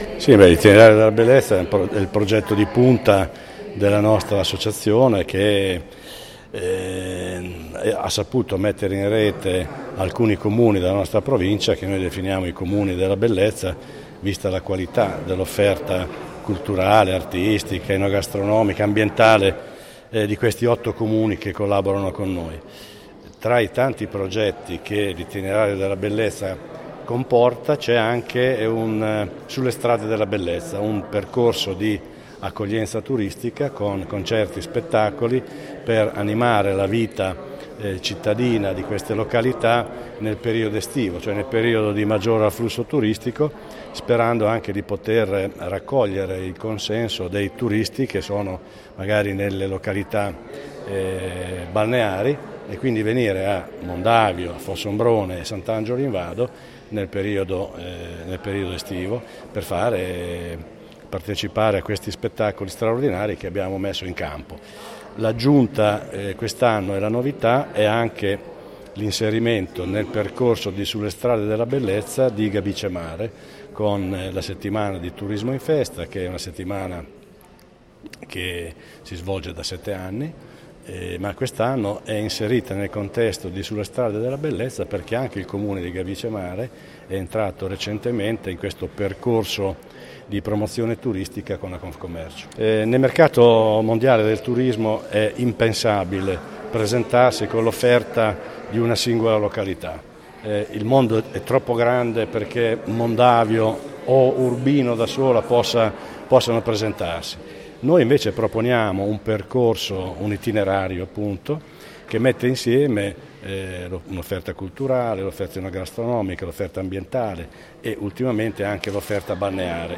Partito il 1° Luglio  e in programma fino al 30 Agosto 2019, una ricca proposta di musica live itinerante e di qualità. Le nostre Interviste a